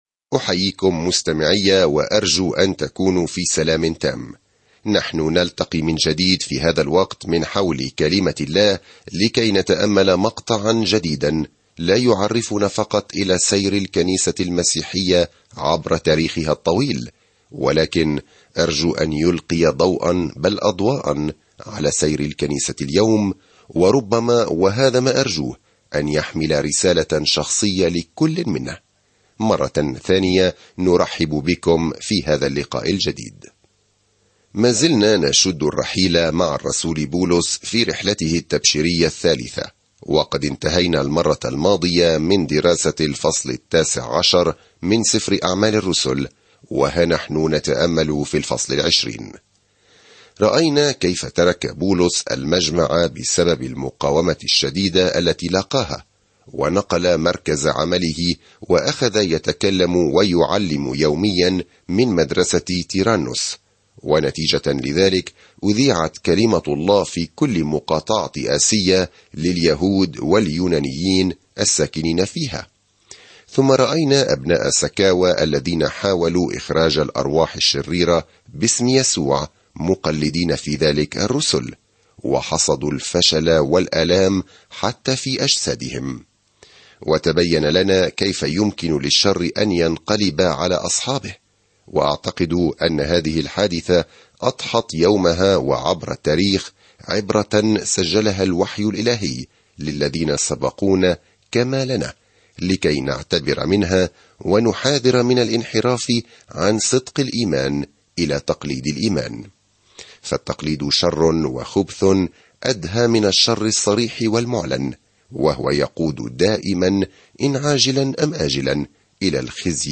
الكلمة أَعْمَالُ ٱلرُّسُلِ 20 يوم 24 ابدأ هذه الخطة يوم 26 عن هذه الخطة يبدأ عمل يسوع في الأناجيل ويستمر الآن من خلال روحه، حيث تُزرع الكنيسة وتنمو في جميع أنحاء العالم. سافر يوميًا عبر سفر أعمال الرسل وأنت تستمع إلى الدراسة الصوتية وتقرأ آيات مختارة من كلمة الله.